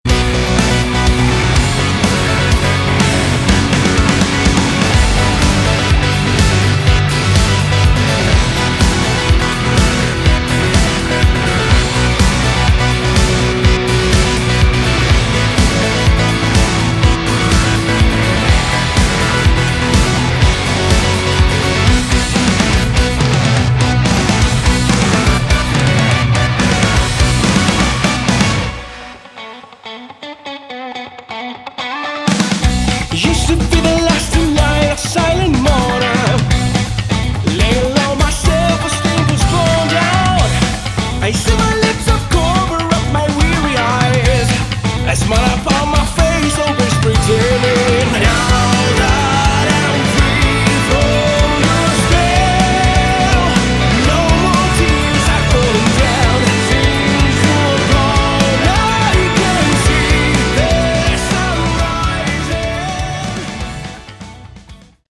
Category: Rock
keyboards, backing vocals